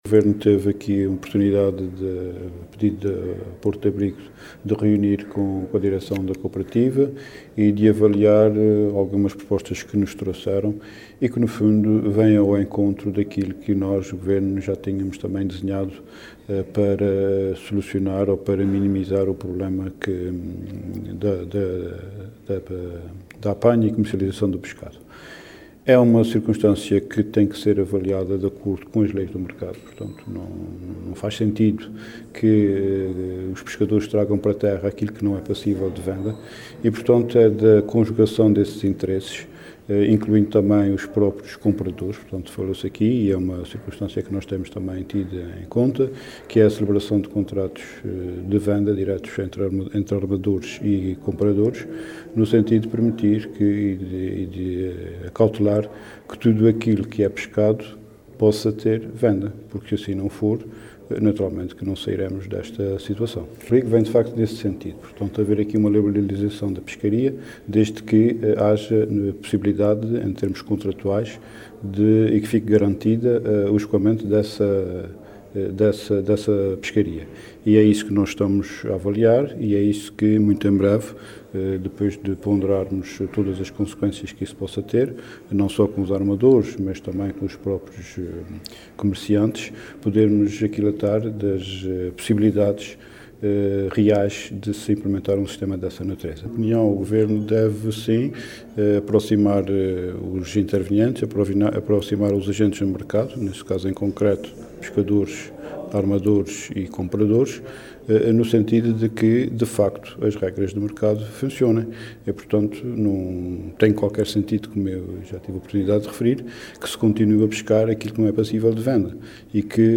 “Não faz sentido que os pescadores tragam para terra aquilo que não é passível de venda”, disse Luís Neto Viveiros, em declarações aos jornalistas, em Ponta Delgada, no final de uma reunião com a Cooperativa Porto de Abrigo.